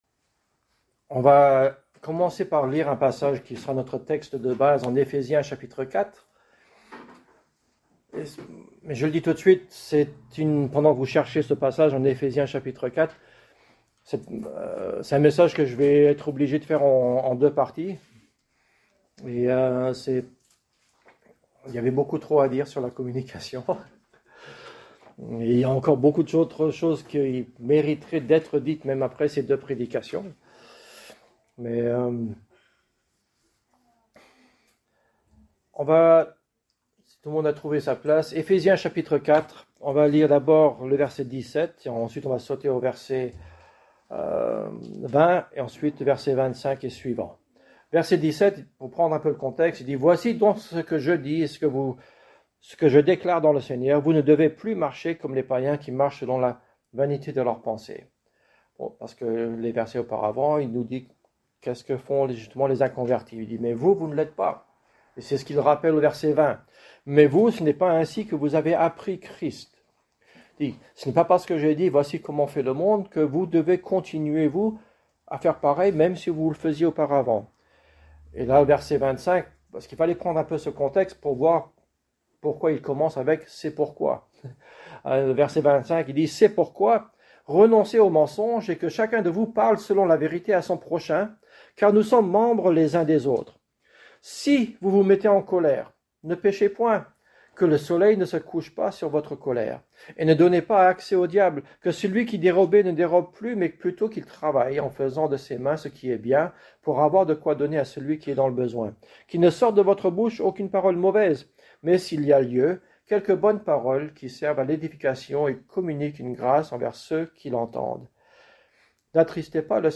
Thème: Communication , Famille Genre: Prédication